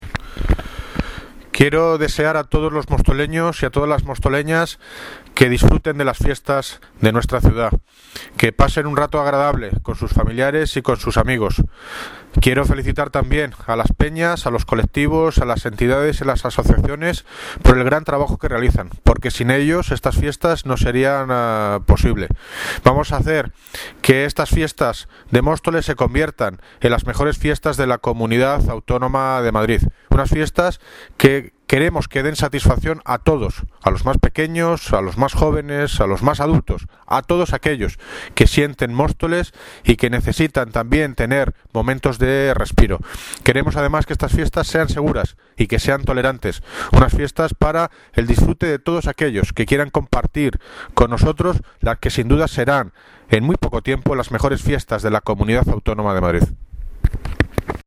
Audio - David Lucas (Alcalde de Móstoles) Felicita las Fiestas a vecinos, peñas y asociaciones